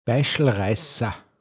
pinzgauer mundart
Baischlraissa, m. Zigarette (sehr starke)